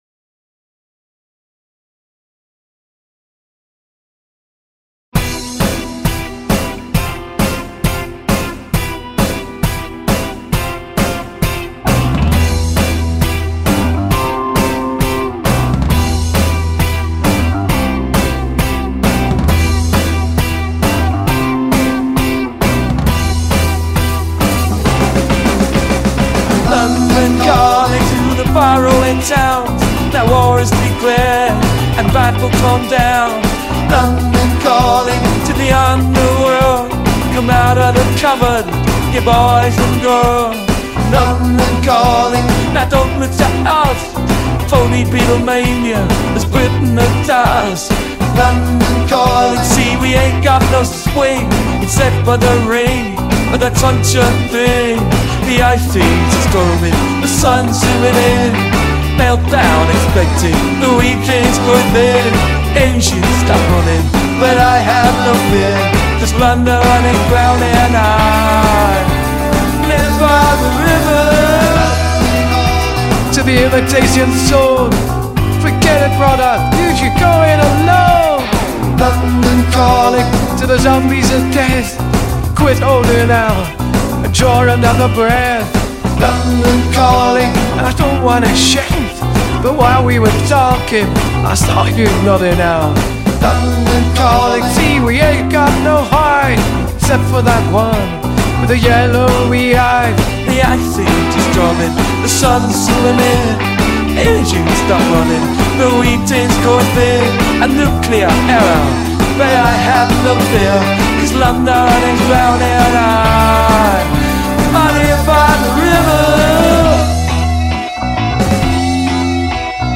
Un duo assolutamente inedito
su una semplice base karaoke.
An absolutely unprecedented duo
over a simple karaoke base.